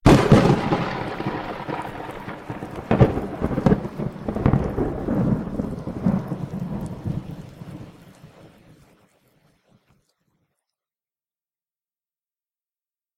Lightning2.mp3